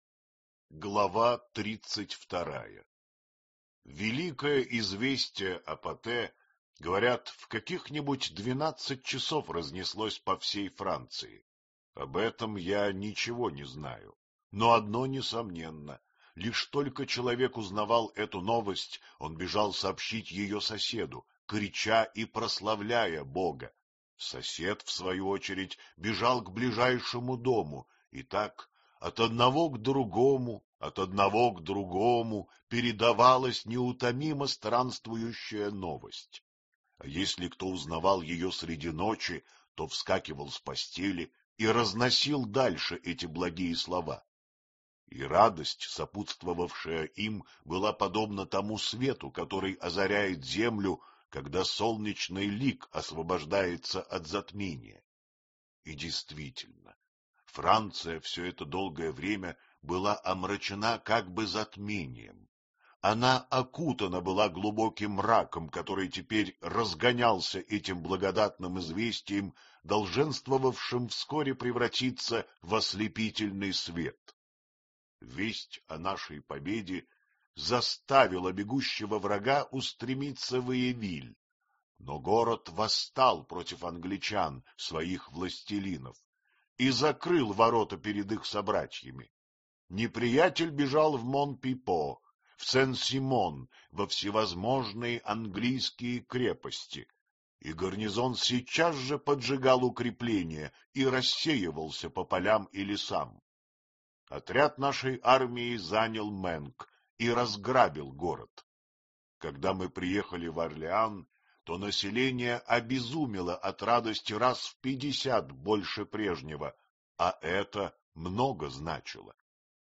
Аудиокнига Жанна д'Арк | Библиотека аудиокниг